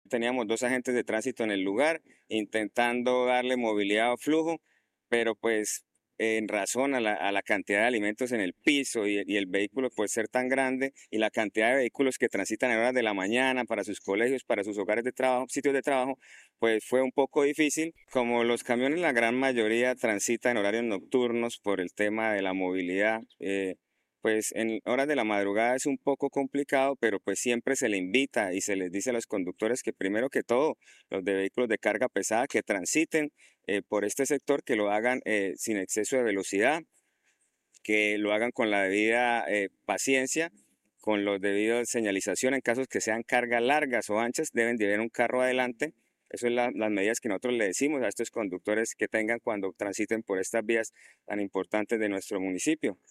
Jahir Castellanos, director de Tránsito de Bucaramanga